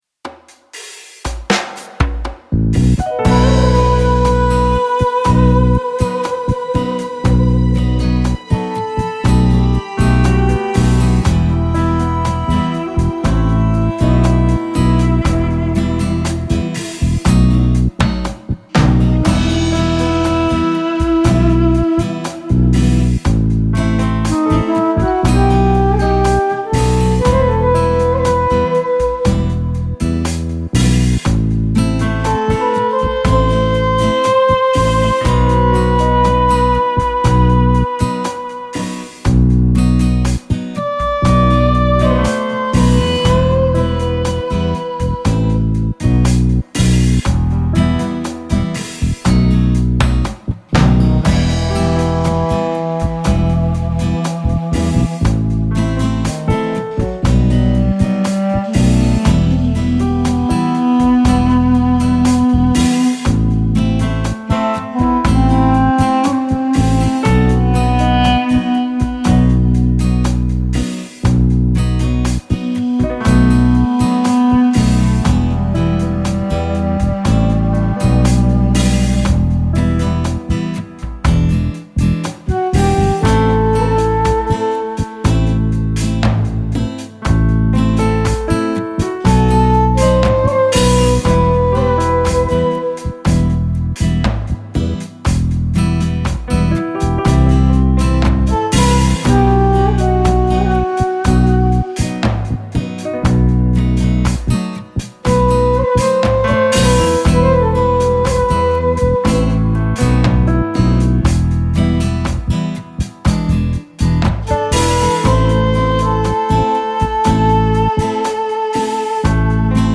Composed in an old popular Malaysian style